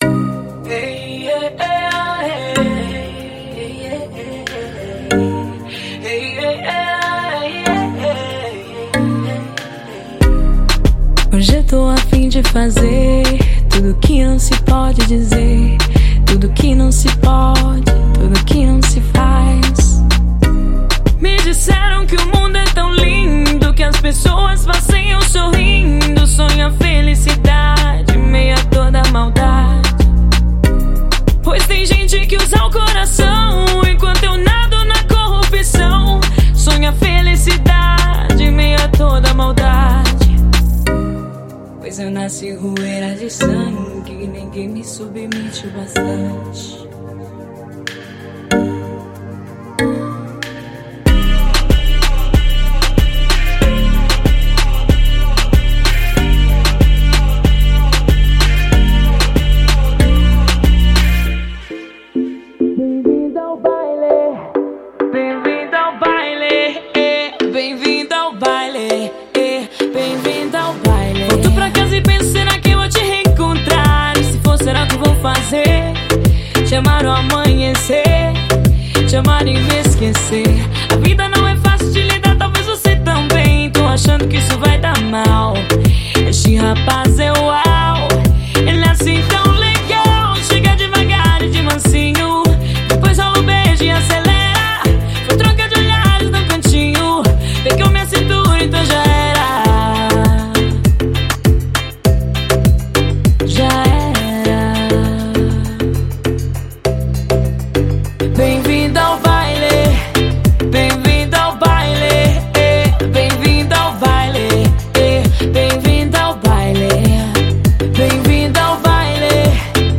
•92 – 100 bpm